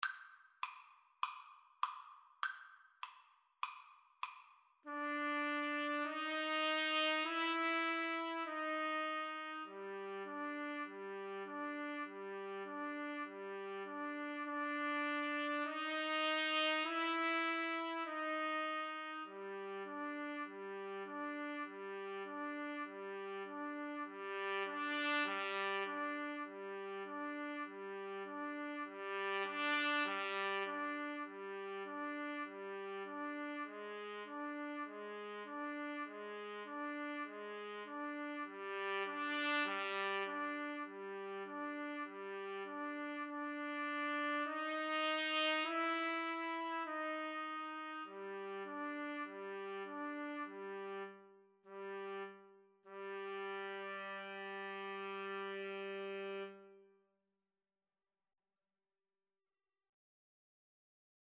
4/4 (View more 4/4 Music)
Jazz (View more Jazz Trumpet Duet Music)